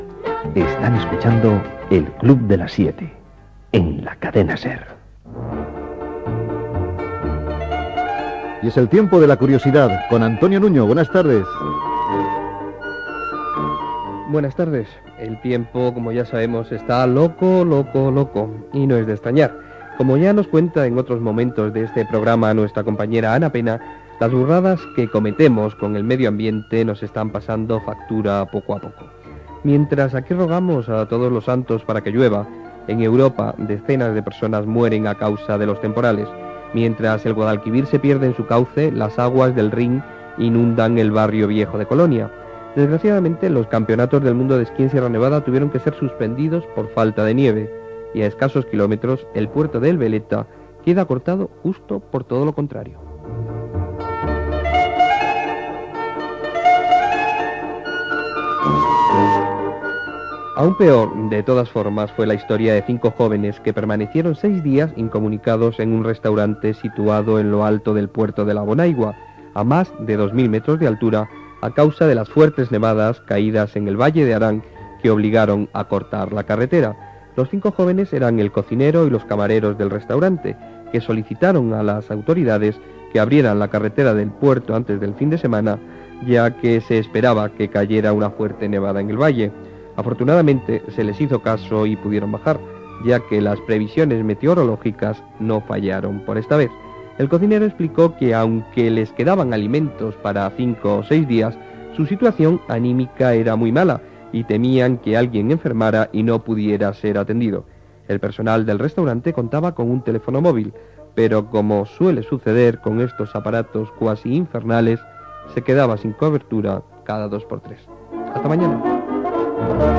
Indicatiu del programa, "El tiempo de la curiosidad", indicatiu, entrevista de Pepe Domingo Castaño als cantants Alberto Cortez i Facundo Cabral .
Entreteniment